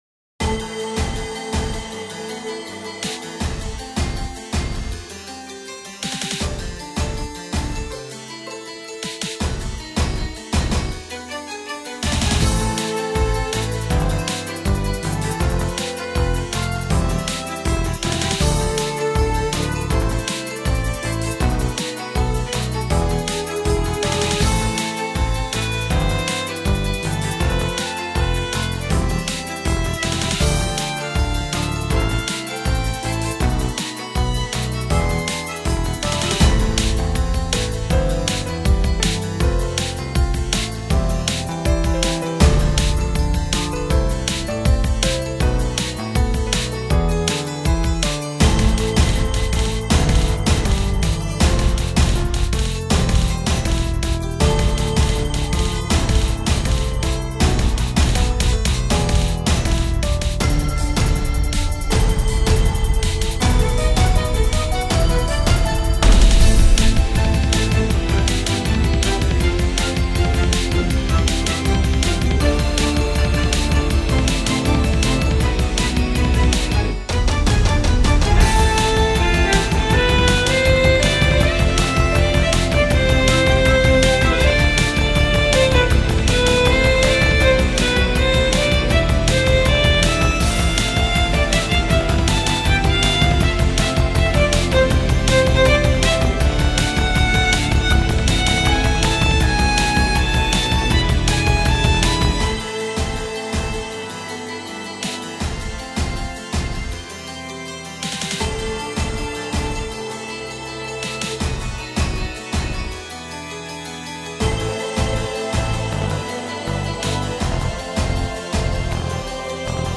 「光と闇の対比」をテーマに制作したダーク・クラシカル系のインスト。
• テンポ：ゆったりとしたBPM（静と動の落差を際立たせる）